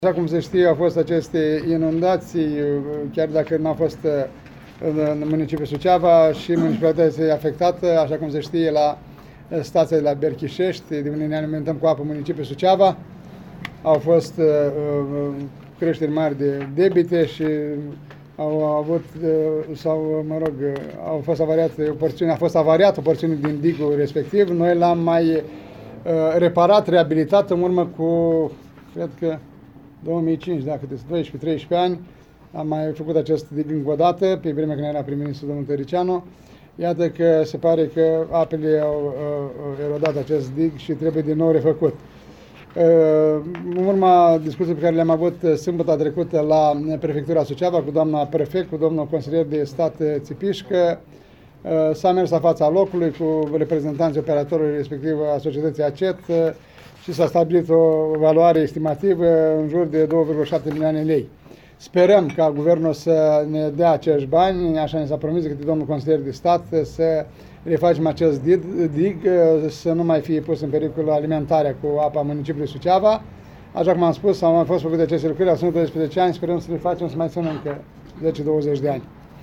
Primarul Sucevei, Ion Lungu, a declarat astăzi că speră ca Guvernul să aloce banii necesari pentru refacerea digului, pentru ca să nu fie pusă în pericol alimentarea cu apă potabilă a municipiului: